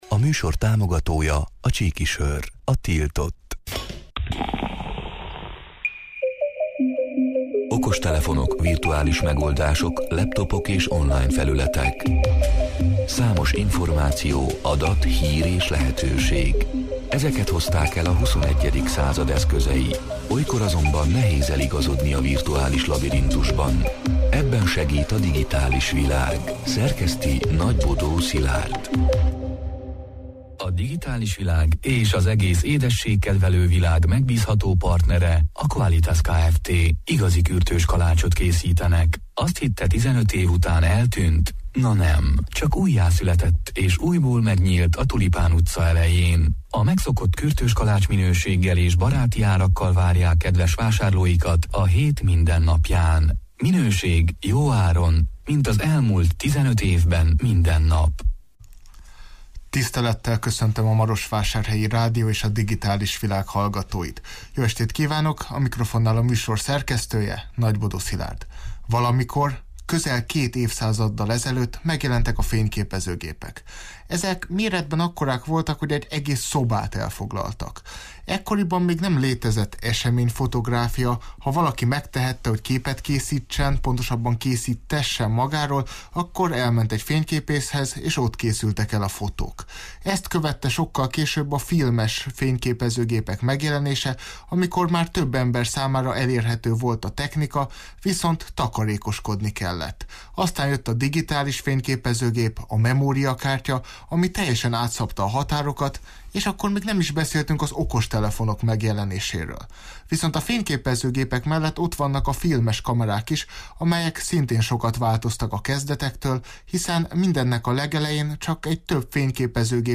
A Marosvásárhelyi Rádió Digitális Világ (elhangzott: 2022. november 22-én, kedden este nyolc órától élőben) c. műsorának hanganyaga: